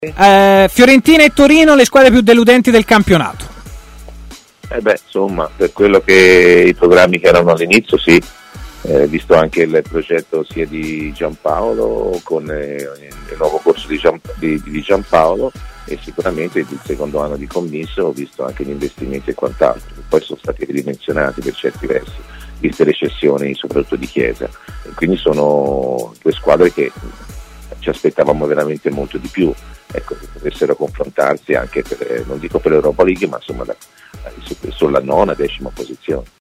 L'ex centrocampista Antonio Di Gennaro, opinionista di TMW Radio, è intervenuto in diretta durante Stadio Aperto. La prima domanda è su Fiorentina e Torino come delusioni del campionato: "Per i programmi che avevano all'inizio sì, da una parte Giampaolo e dall'altra gli investimenti ridimensionati di Commisso.